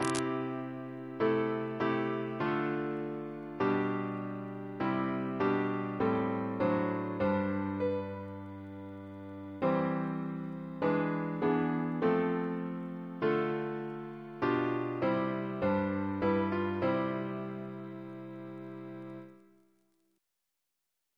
Double chant in C Composer: Edward John Hopkins (1818-1901), Organist of the Temple Church Reference psalters: ACB: 29; ACP: 290; CWP: 33; OCB: 108; PP/SNCB: 197; RSCM: 72